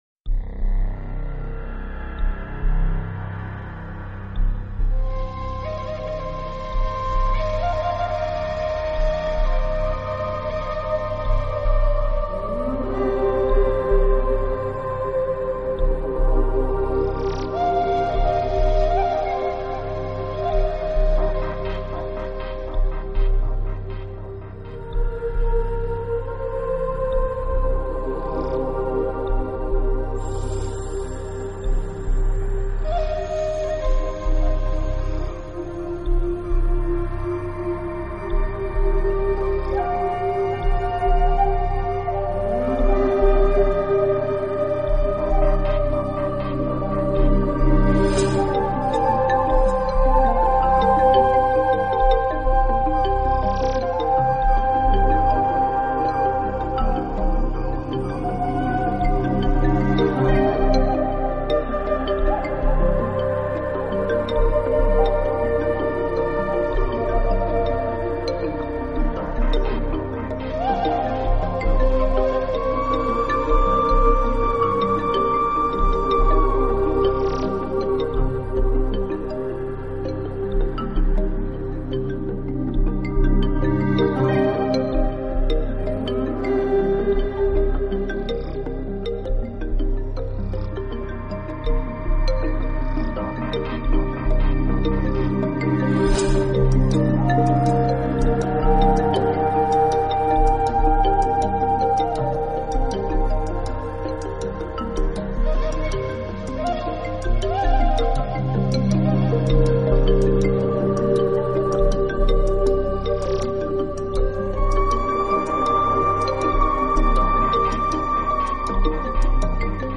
【新世纪音乐】
最好听的一首，随着乐曲的播放，音乐元素的编排越来越丰富，仿佛刮过一阵劲风让